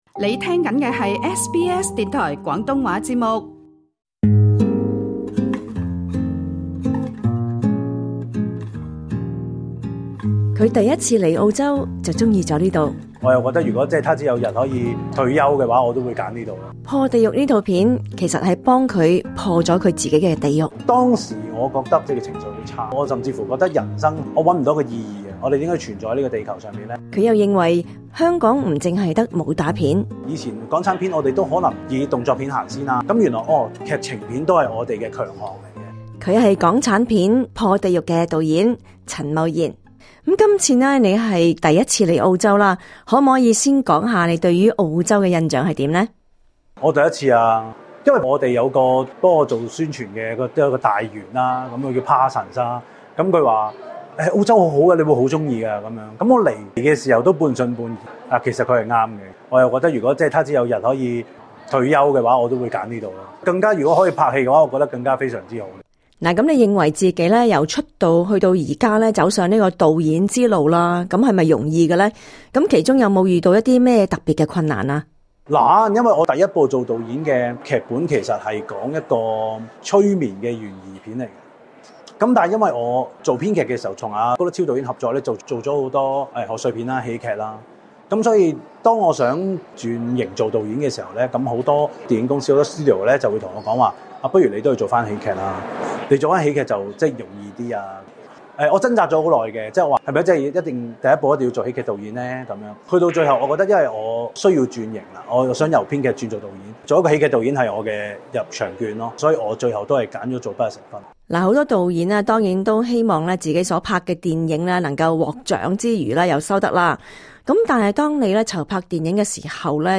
早前來澳洲宣傳他的電影《破·地獄》的香港導演陳茂賢接受了廣東話組記者訪問， 談到他成為導演的心路歷程以及這套電影對他自己究竟帶來甚麼影響？